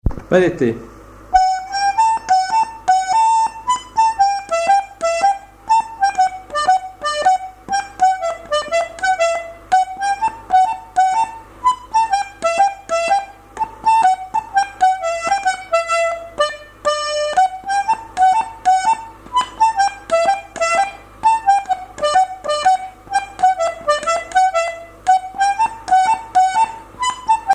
danse : pas d'été
airs de danse à l'accordéon diatonique
Pièce musicale inédite